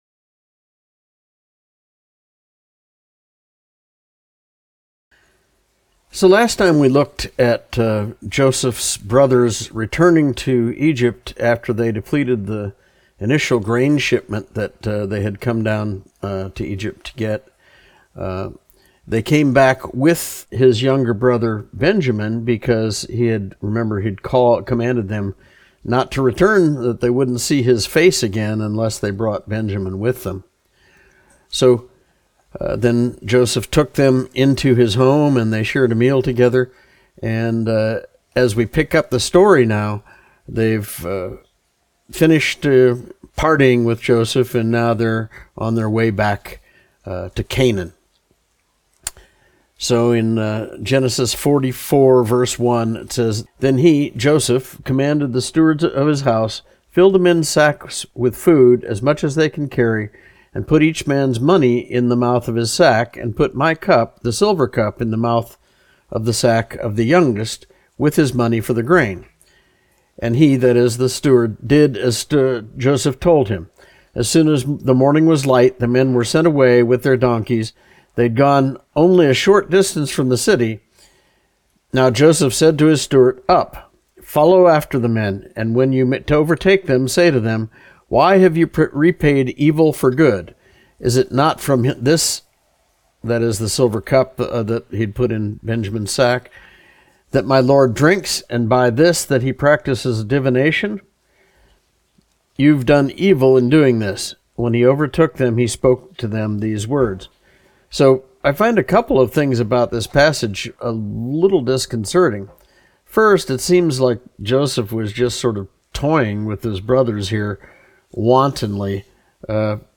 Study Type - Adult Lesson